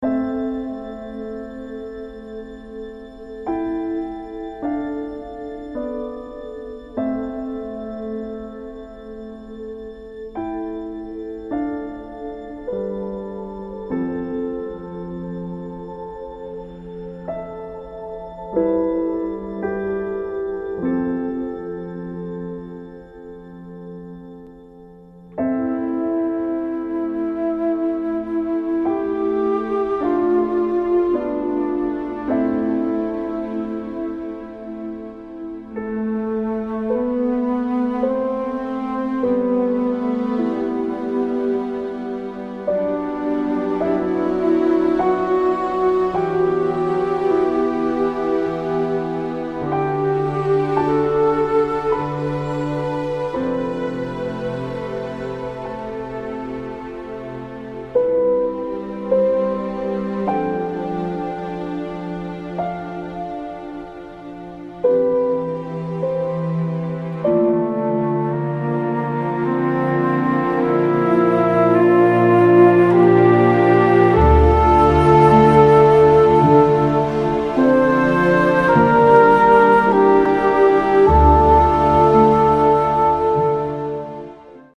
Score soundtrack
genuinely moving